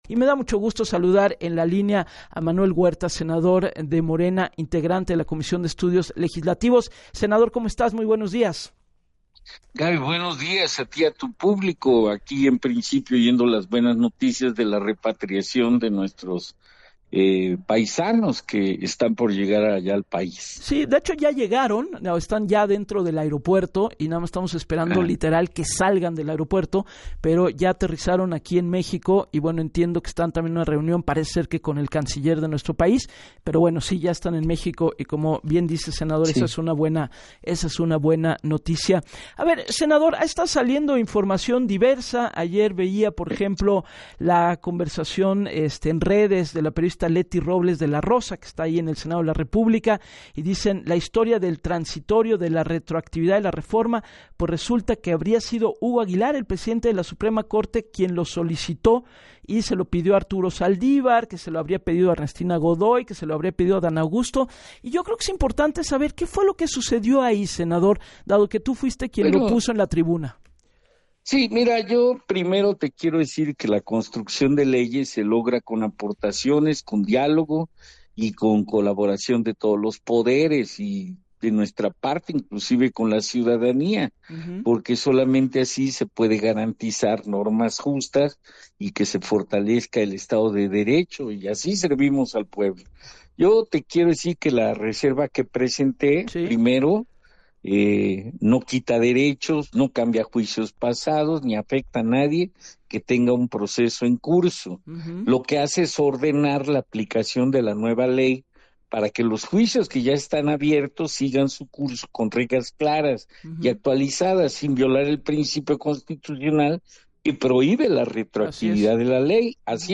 Puntualizó en entrevista con Gabriela Warkentin para “Así las Cosas“, que se deben seguir las reglas “sin violar la constitucionalidad”, porque aseguró “nadie va a perder un derecho ganado, su caso seguirá bajo las mismas reglas, trámites que no se han hecho podrán seguir las nuevas normas que evitan el abuso”.